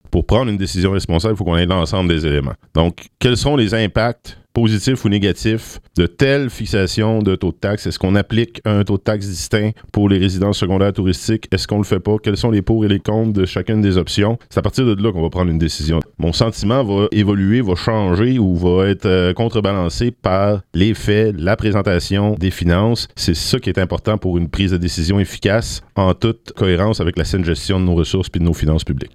Le maire des Îles et président de la Communauté maritime, Antonin Valiquette, a été invité à clarifier ses intentions à cet égard, mais refuse de se positionner.